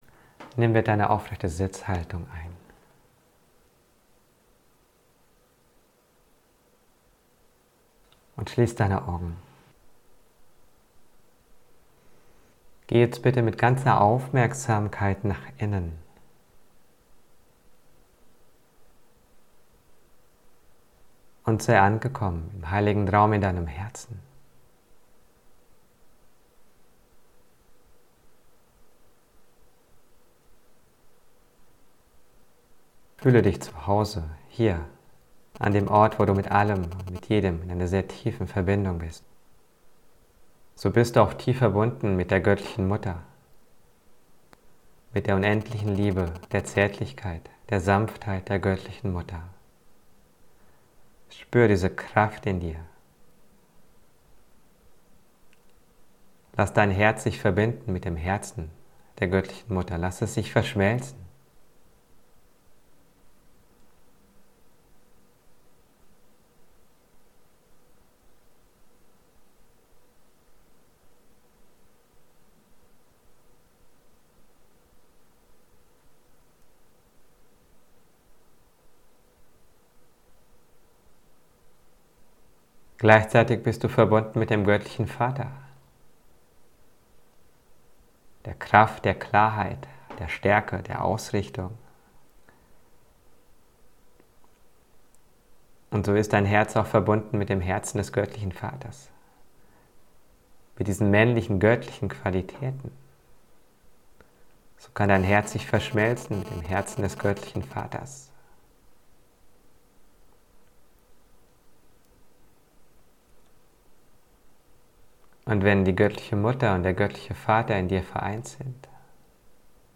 KWW_DEZ22_MEDITATION_MULTIDIMENSIONALES_ERWACHEN_NR3.mp3